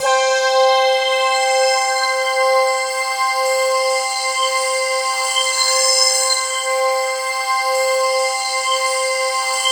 BRASSPADC5-L.wav